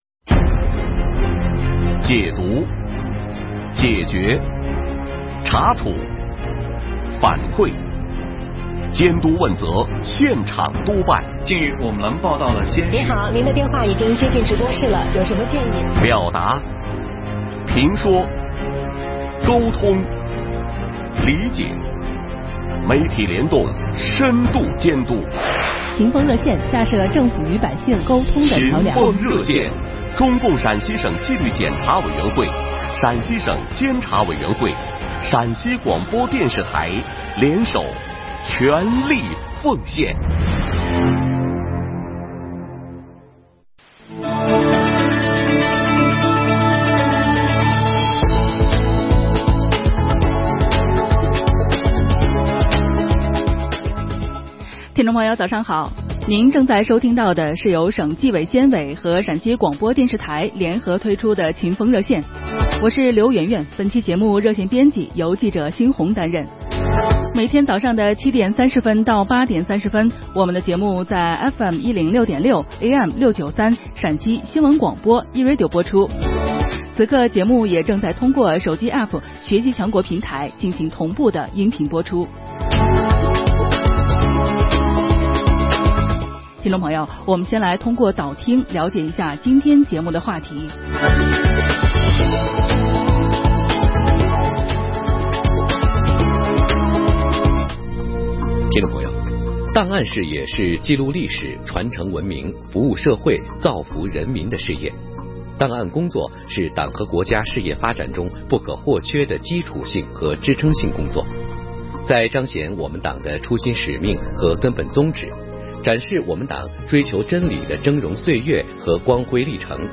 省档案馆受邀做客陕西新闻广播《秦风热线》直播间
省档案馆做客《秦风热线》直播间全程音频.mp3